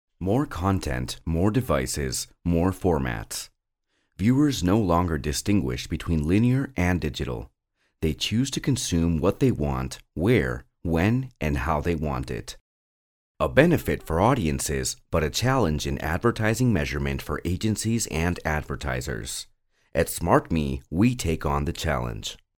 North American male voiceovers. Group B